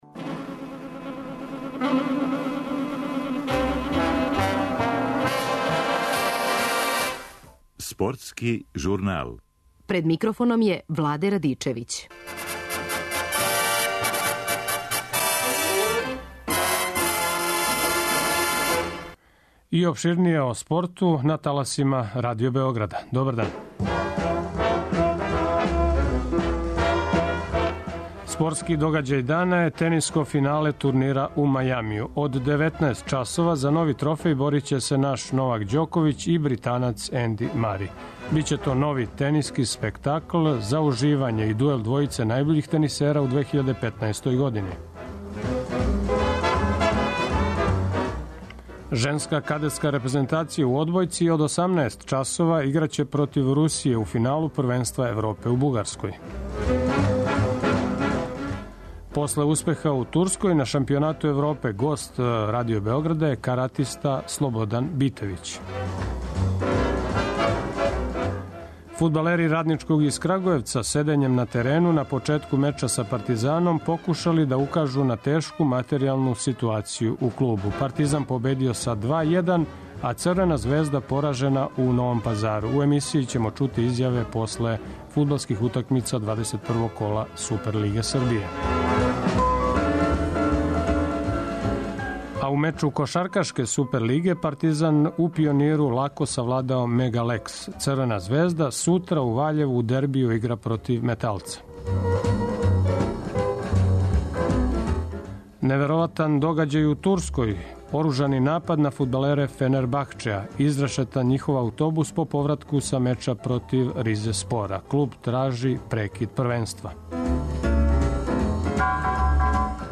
Партизан је победио са 2-1, а Црвена звезда поражена у Новом Пазару. Чућемо изјаве после фудаблских утакмица.